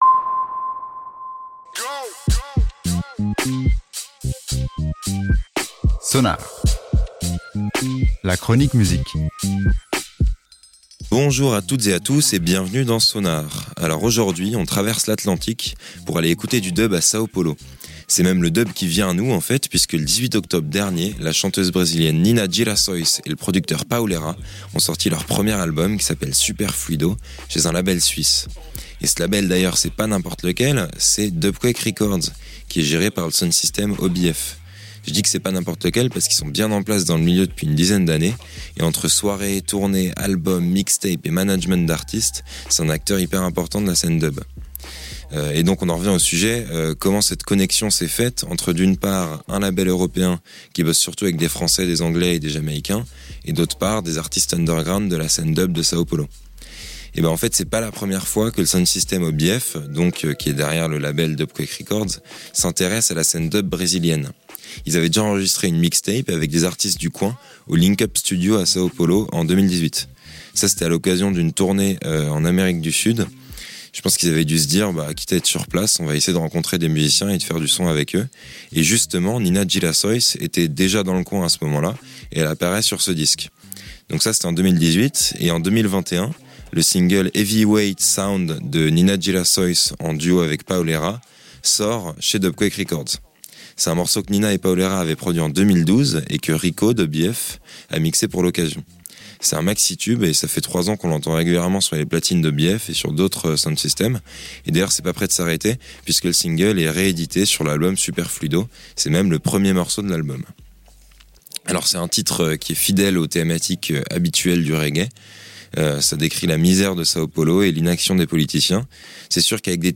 C'est un super album de reggae digital.